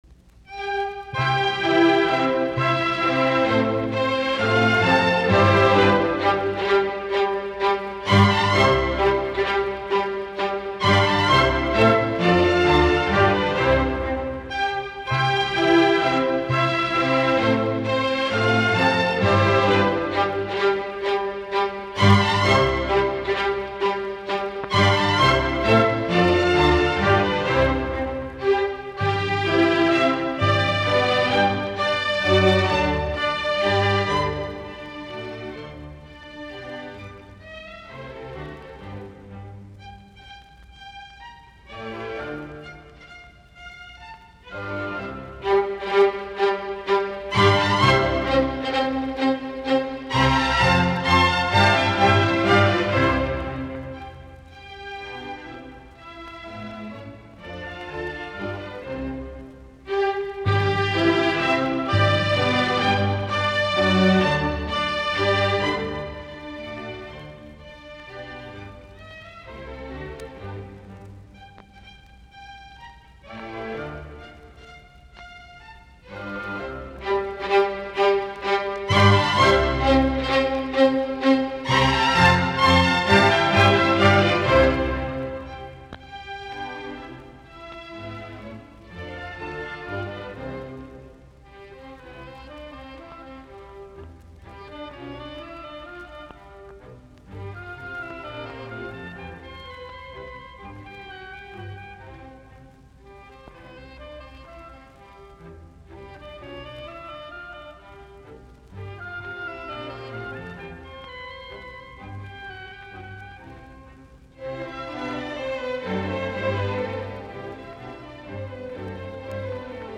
3. Menuetto - Trio